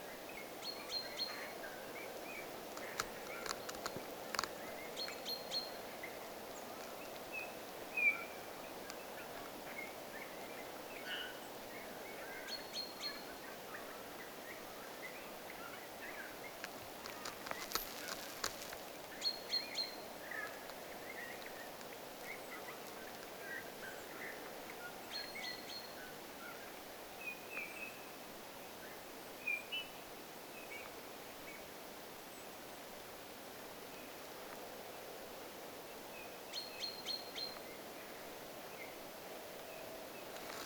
talitiaisen peippomaisia ääntelyjä
talitiaisen_peippomaista_aantelya.mp3